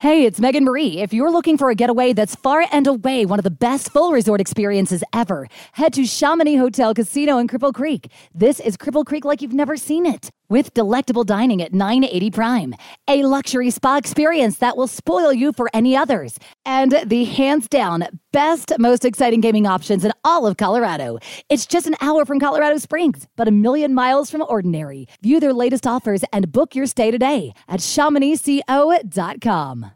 Voice Sample 1: